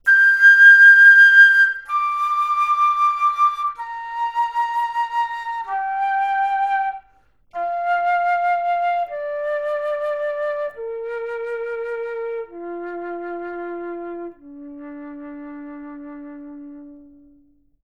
Play Flute coded
wavelet filter of length=40, 64 subbands